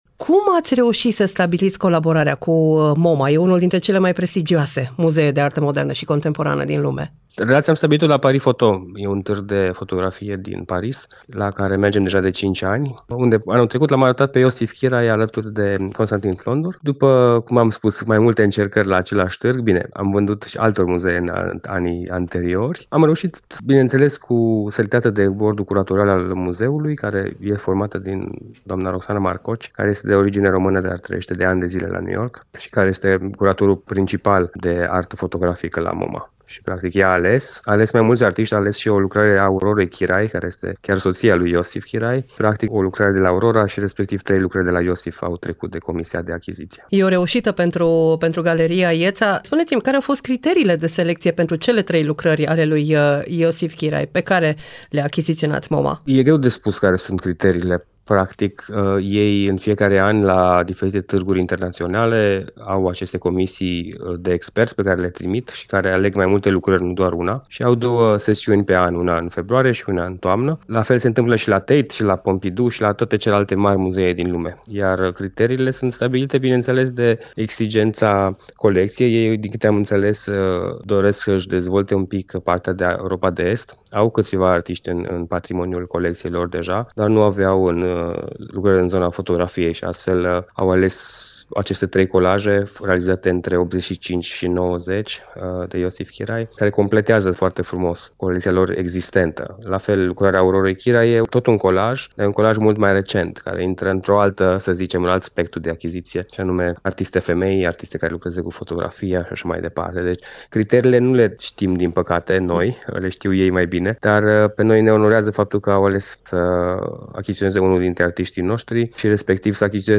INTERVIU | Arta românească pe scena internațională